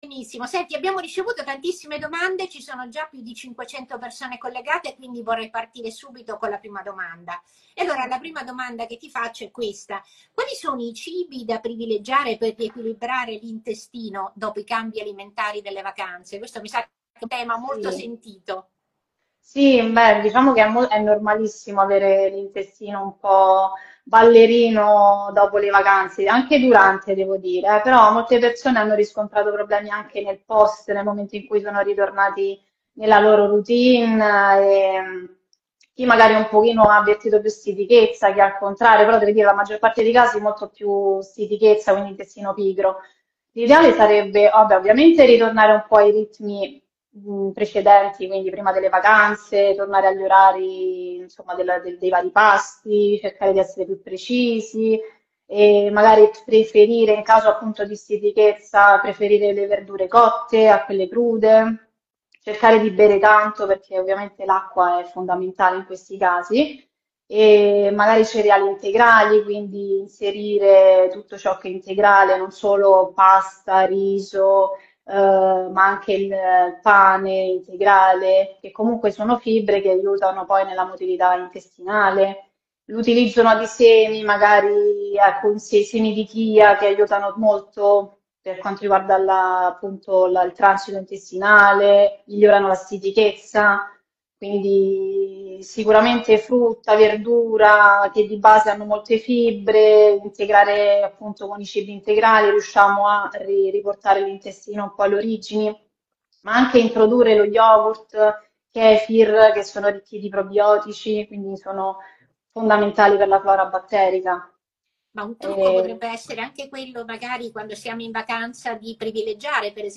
Come rimettersi in forma dopo le vacanze un evento in diretta social